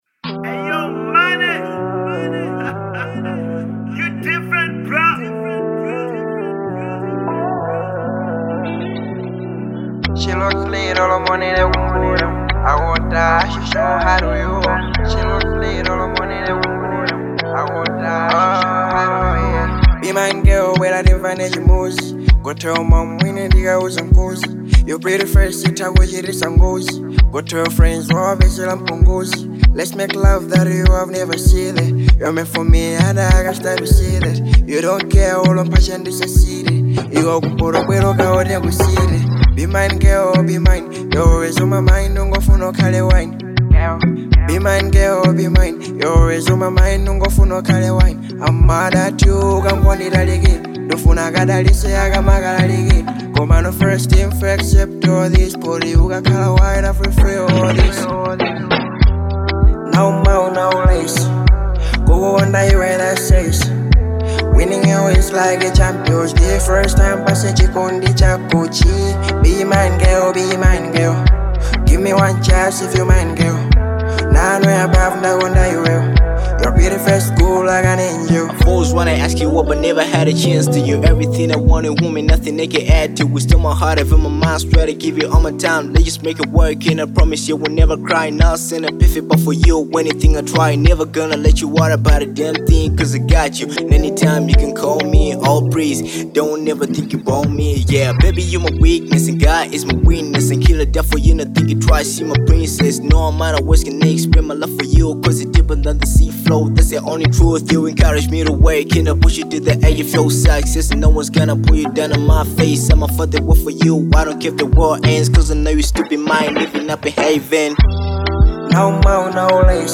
Genre : Afro Soul